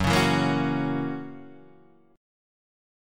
F#7 chord